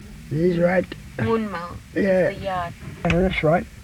The specific epithet (pronounced ‘dim-baan goon-mal’) comes from dhimba (‘sheep’) and ngunmal (‘yard’) in the language of the Yuwaalaraay, Yuwaalayaay, and Gamilaraay peoples, after the Sheepyard opal field where the bone bed is located.”
DHIM-ba-ngun-mal